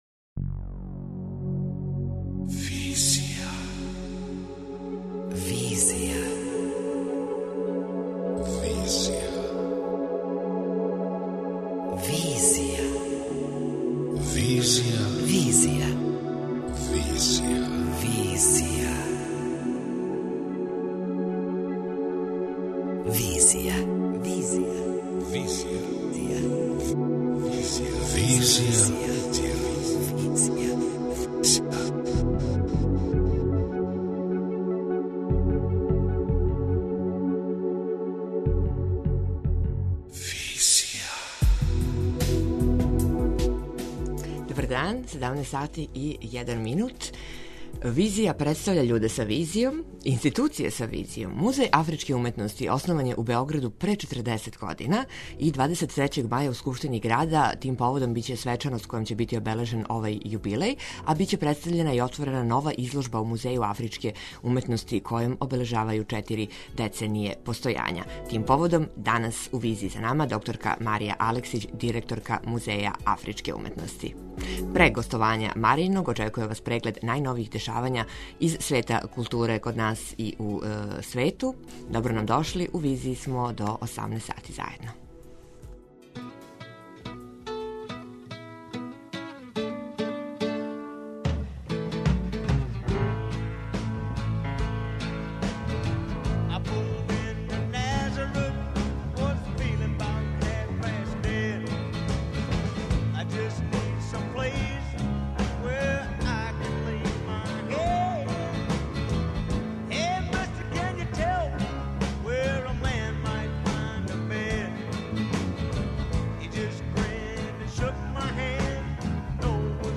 преузми : 27.36 MB Визија Autor: Београд 202 Социо-културолошки магазин, који прати савремене друштвене феномене.